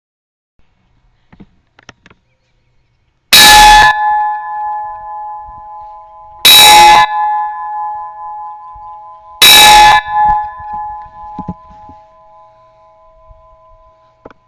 Bell Ringing.wma